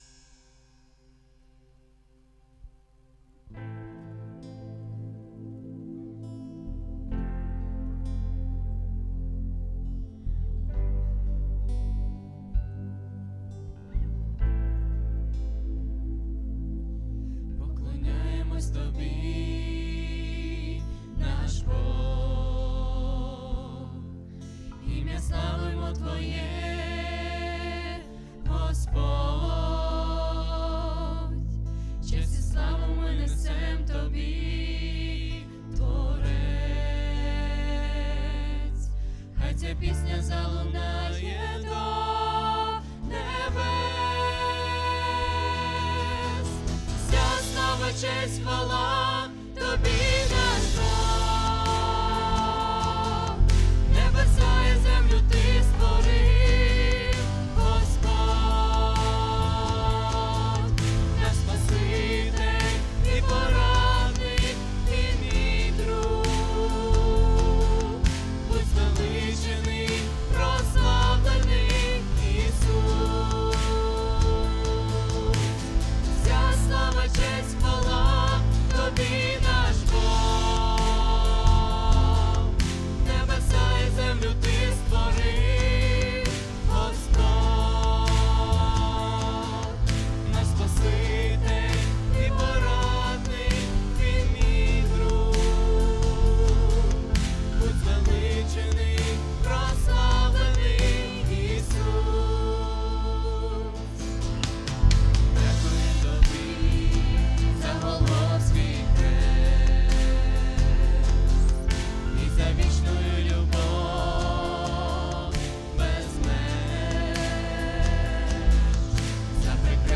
3005 просмотров 438 прослушиваний 138 скачиваний BPM: 132